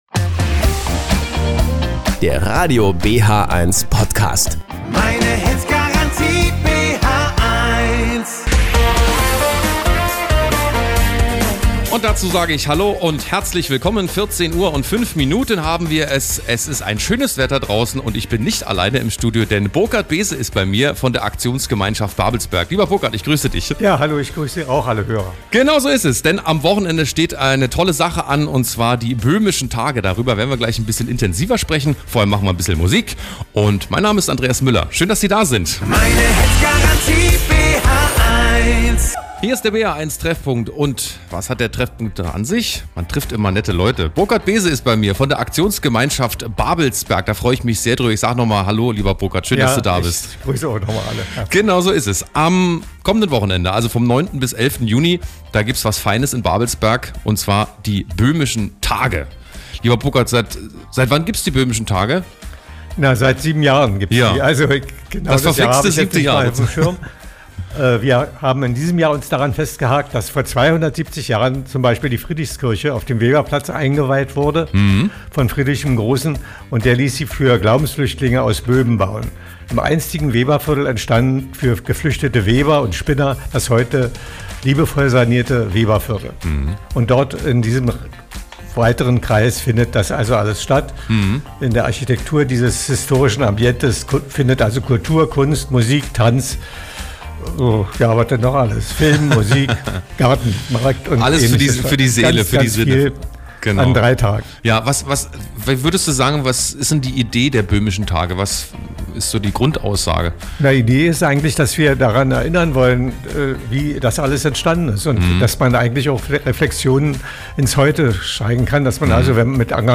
Im Gespräch geht es um die Böhmischen Tage vom 9.-11. Juni